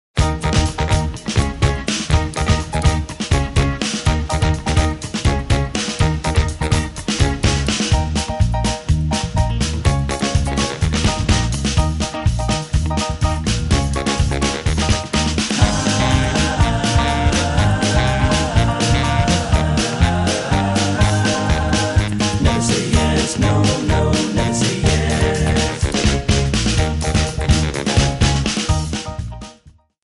Eb
MPEG 1 Layer 3 (Stereo)
Backing track Karaoke
Pop, Oldies, 1960s